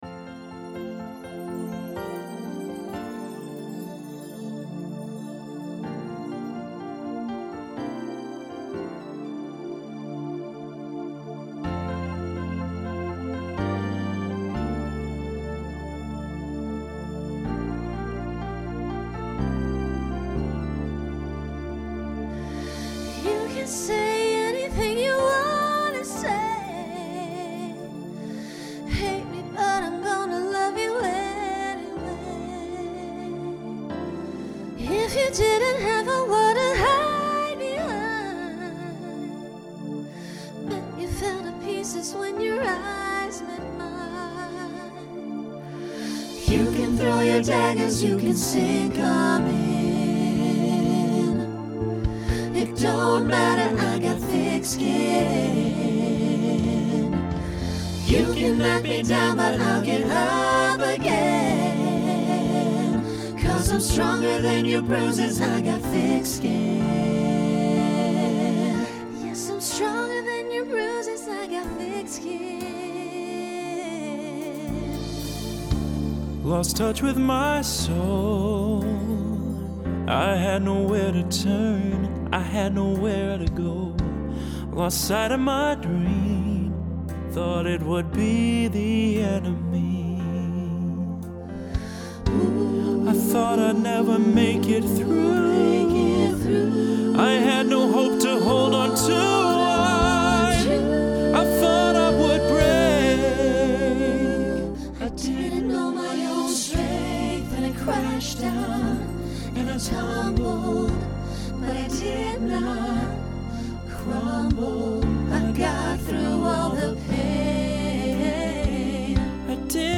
Genre Pop/Dance
Function Ballad Voicing SATB